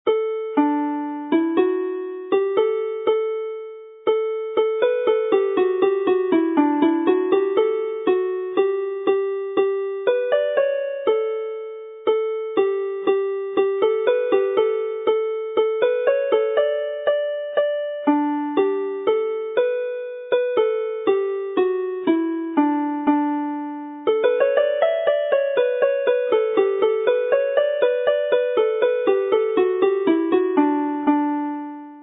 Triban syml yn D - cân ac amrywiad ar y ffidil
Triban in D with fiddle variation in repeat